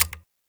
Click (15).wav